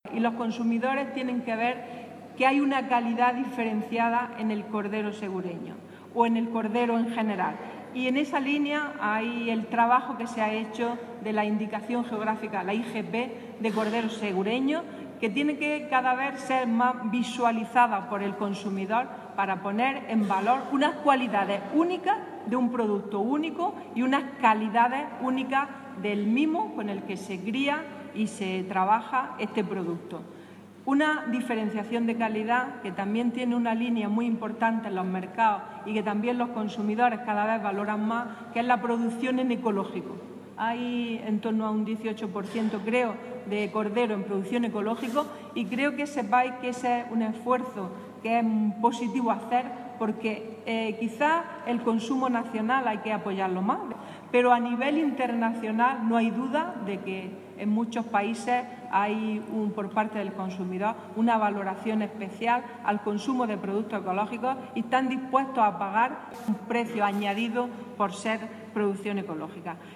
Declaraciones de Carmen Ortiz sobre calidad diferenciada de productos ganaderos